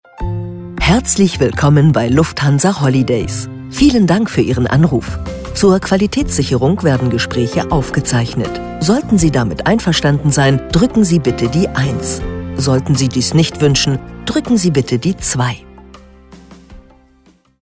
Telefonansage Lufthansa Holidays
IVR Telefonansage Lufthansa Holidays